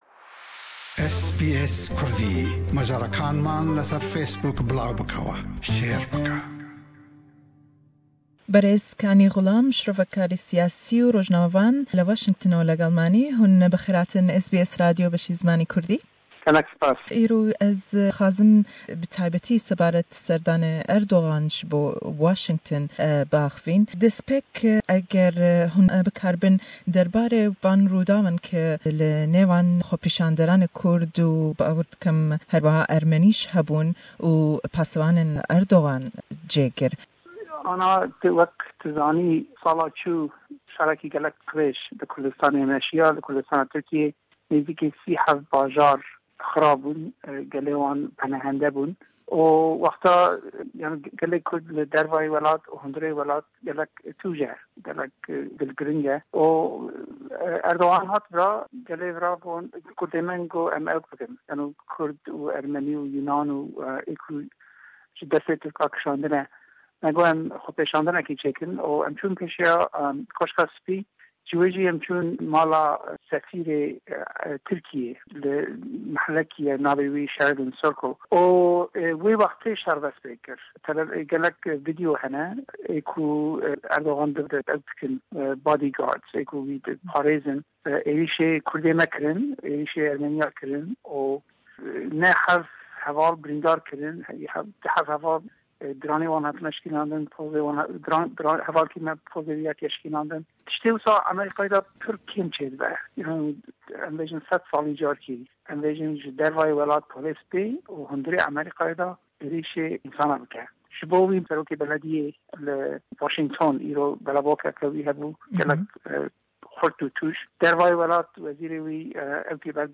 Le em hevpeyvîne da le gell rojnamevan û shirovekarî siyasî